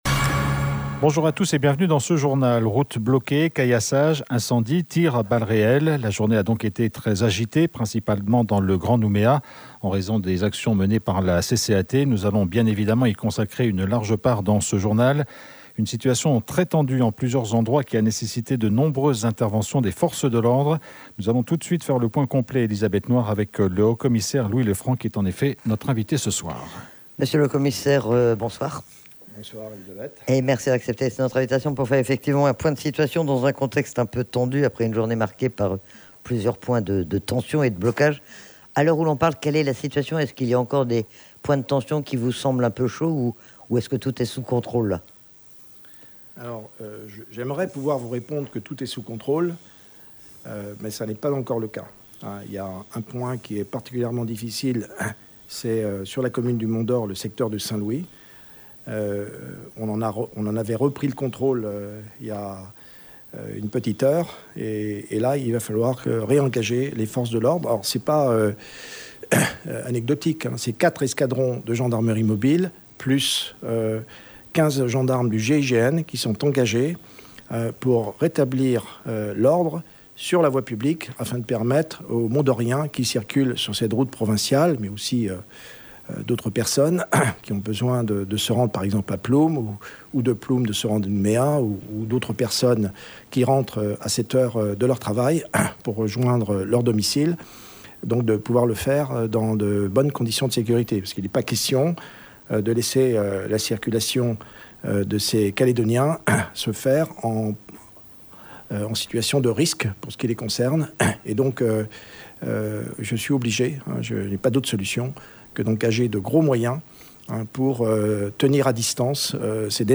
Les forces de l’ordre sont mobilisées nous a dit le Haut-commissaire qui était en direct dans le journal de 17h45. Il a annoncé qu’il avait pris un arrêté pour interdire le transport d’armes et la vente d’alcool pendant 48h.